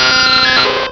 Cri de Nosferapti dans Pokémon Diamant et Perle.